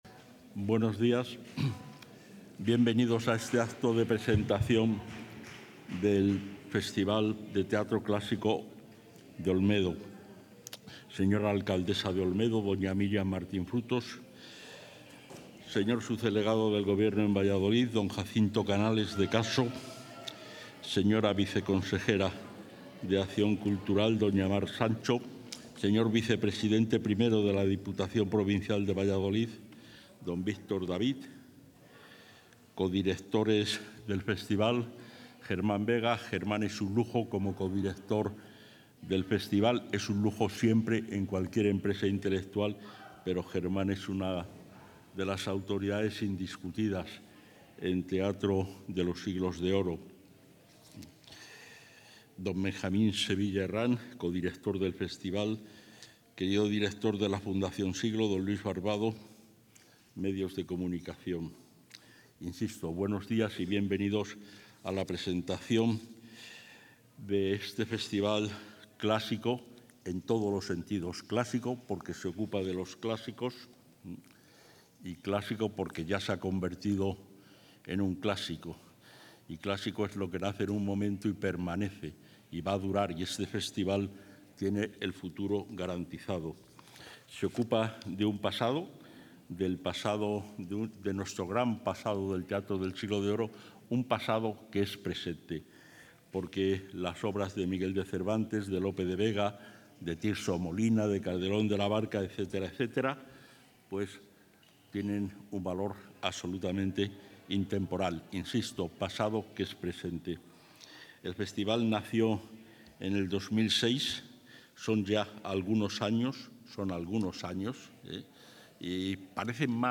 El consejero de Cultura, Turismo y Deporte, Gonzalo Santonja, ha participado hoy en la presentación del XIX Festival de Teatro Clásico...
Intervención del consejero.